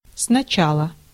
Ääntäminen
IPA: [snɐˈt͡ɕalə]